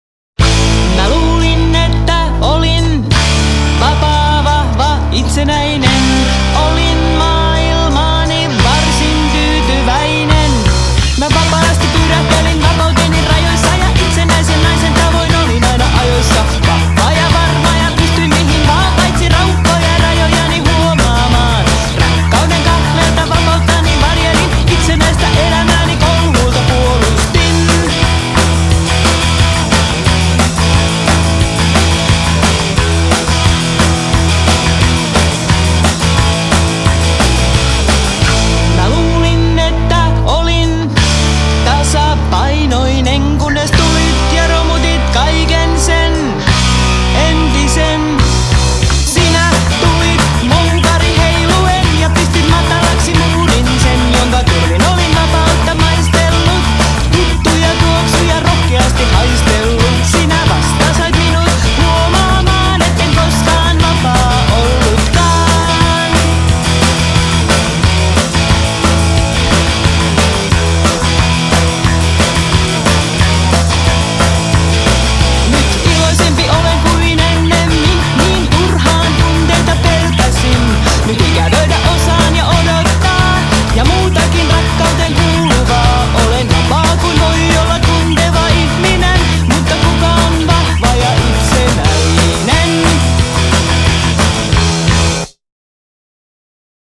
kitara, taustalaulut
basso, taustalaulut
rummut, taustalaulut
treenikämpällä ja Riku-studiossa